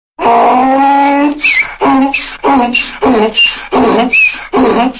funny-donkey.mp3